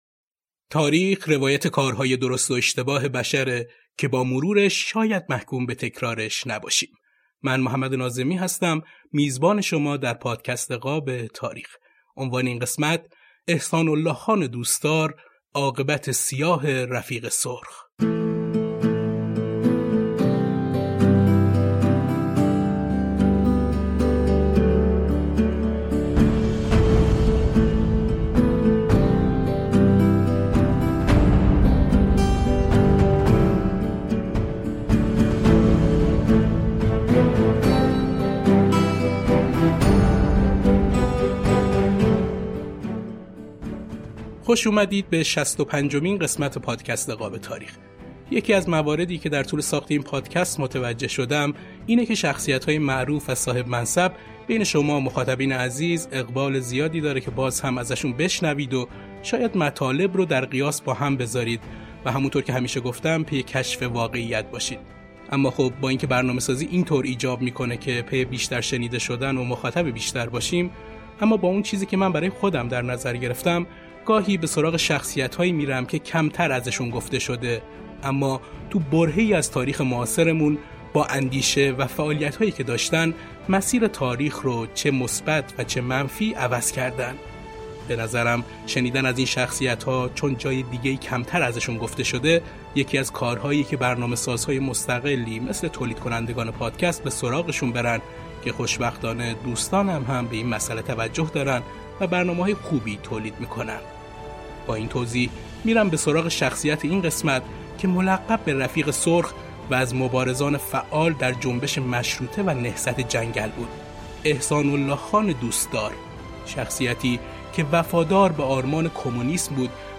موسیقی بی‌کلام